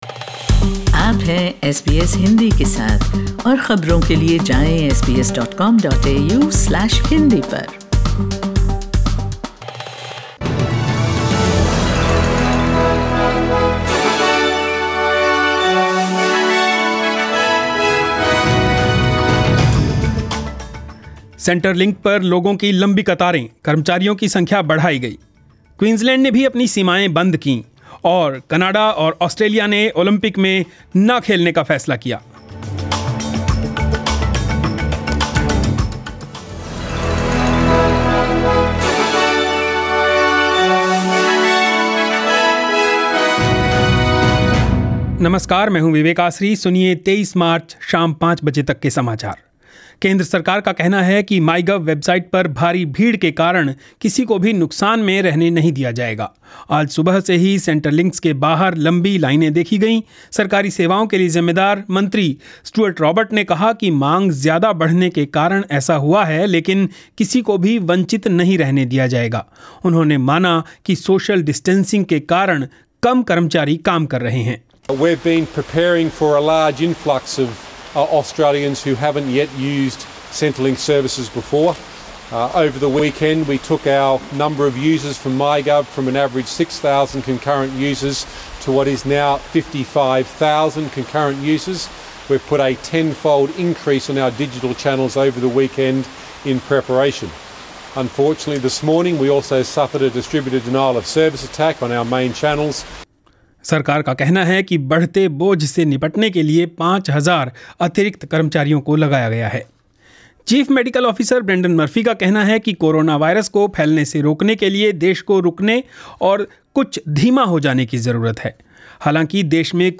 News in Hindi 23 March 2020